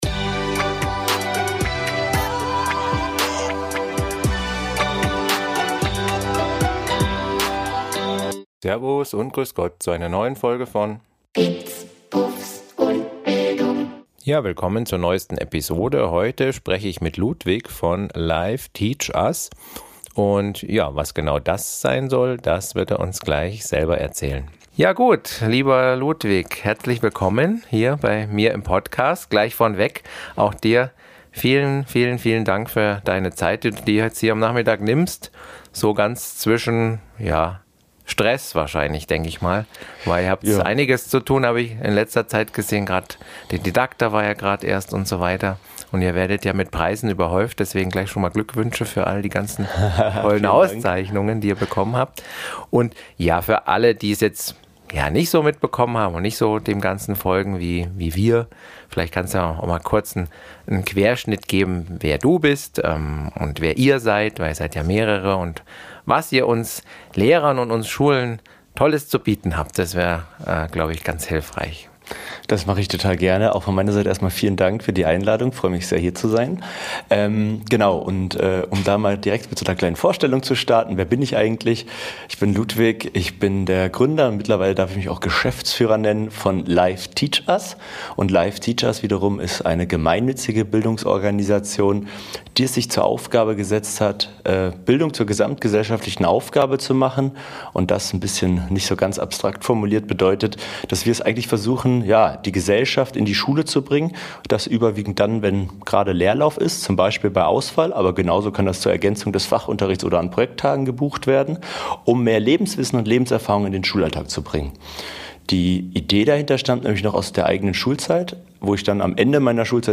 #49 Episode 49 IM GESPRÄCH